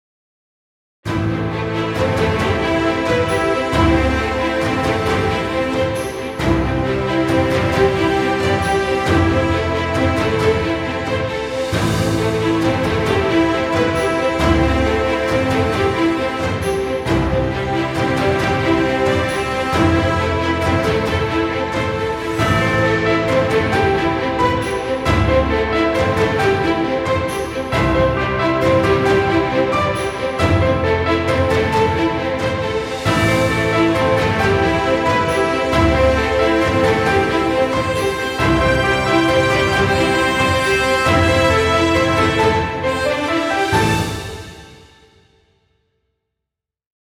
Epic music, exciting intro, or battle scenes.